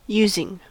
Ääntäminen
Synonyymit via Ääntäminen US : IPA : [juː.zɪŋ] Haettu sana löytyi näillä lähdekielillä: englanti Using on sanan use partisiipin preesens.